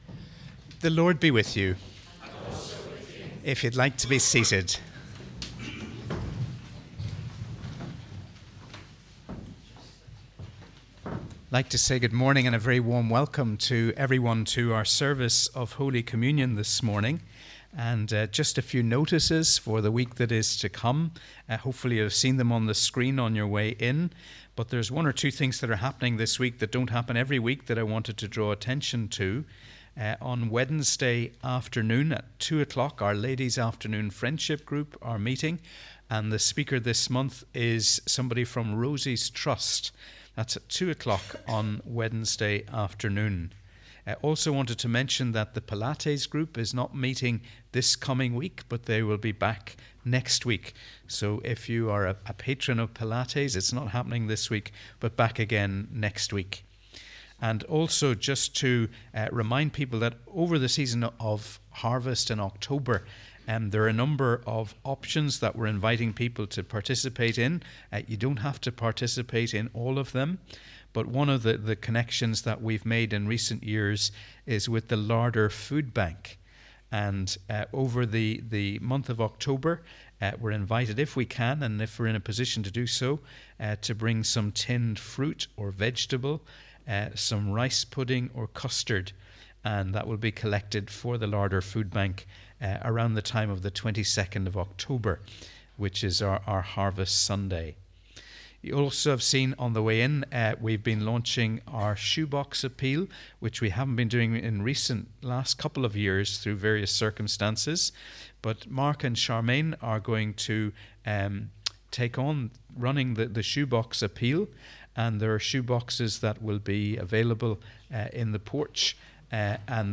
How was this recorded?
Welcome to our service of Holy Communion on the 18th Sunday after Trinity.